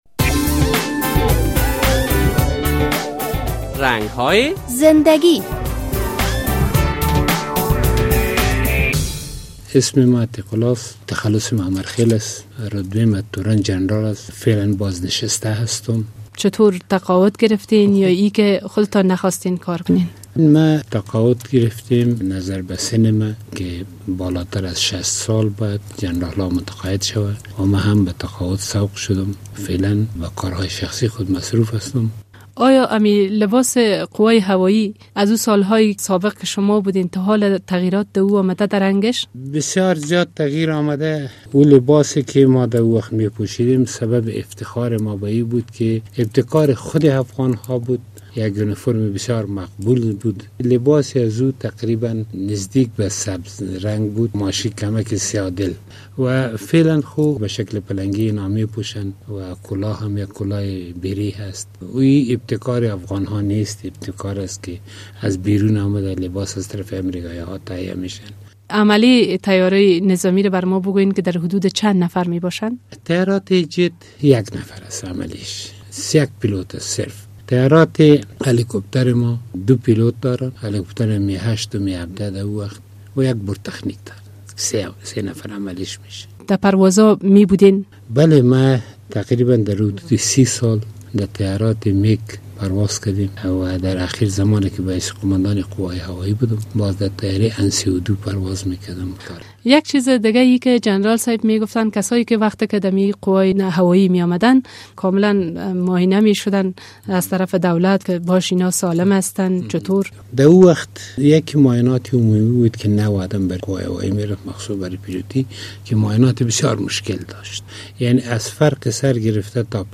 در این برنامهء رنگ های زنده گی با یک تن از جنرالان اسبق افغانستان مصاحبه صورت گرفته است.